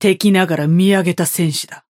Yamato voice line - An admirable warrior, despite being an enemy.